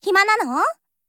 [[Category:Voice lines]]